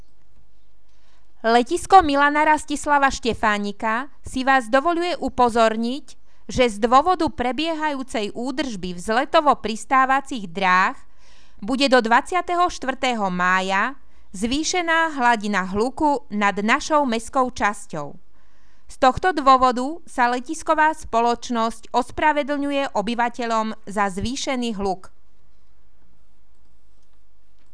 Hlásenie miestneho rozhlasu 20.5.2015